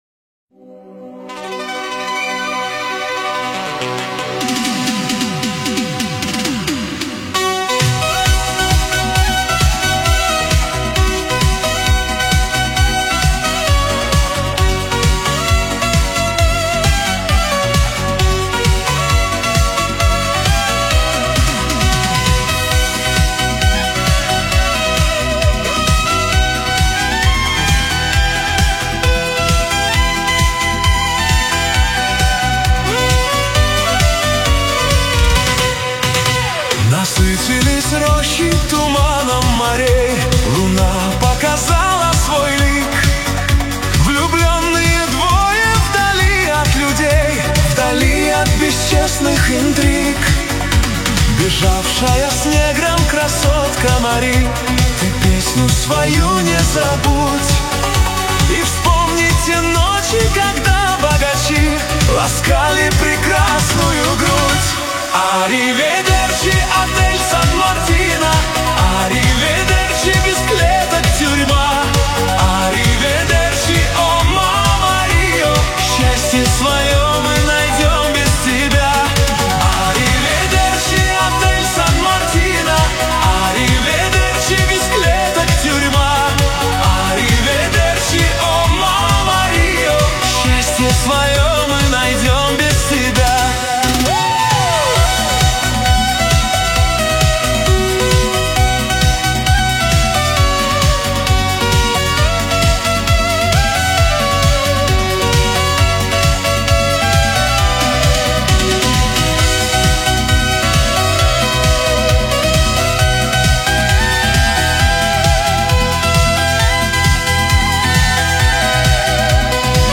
Новинки русской музыки